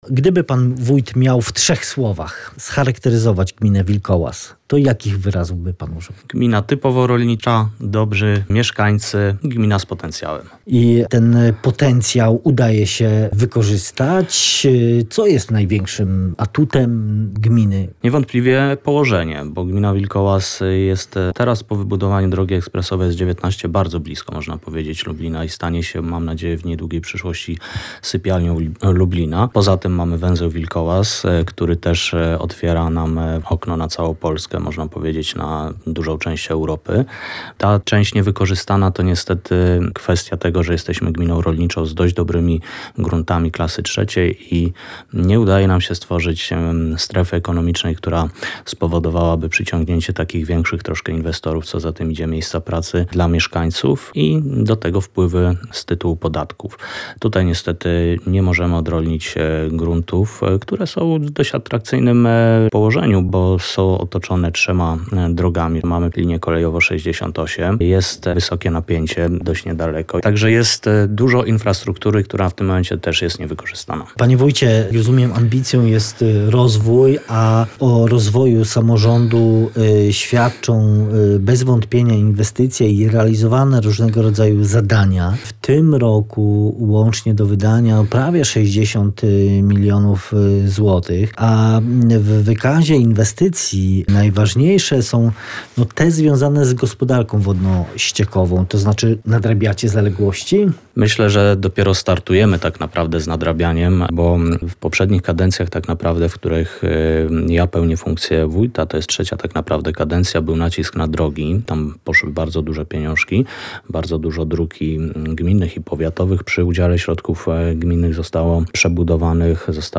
Cała rozmowa z wójtem gminy Wilkołaz w poniższym materiale dźwiękowym: Gmina Wilkołaz może pochwalić się także bogatą historią.